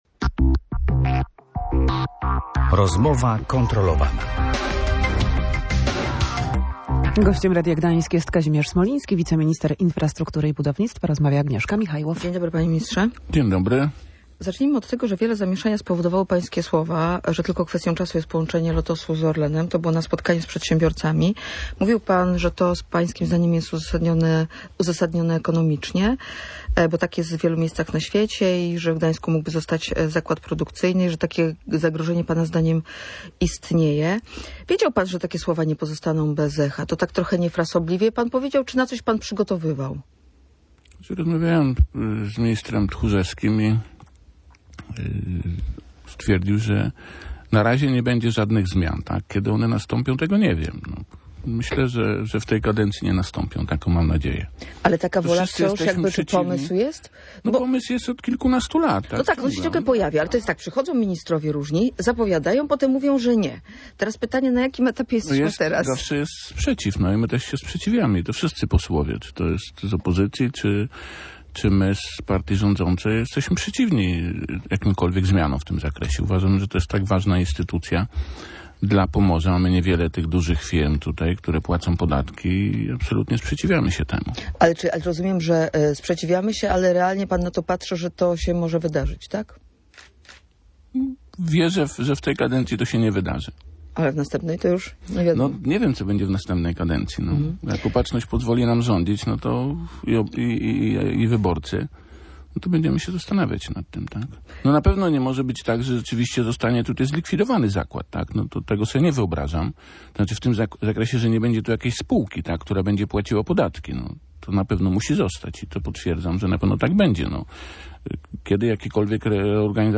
– Wierzę, że w tej kadencji się to nie wydarzy. Ale nie wiem co będzie w następnej kadencji – podkreślał na antenie Radia Gdańsk wiceminister infrastruktury.
Kazimierz Smoliński był gościem Rozmowy kontrolowanej.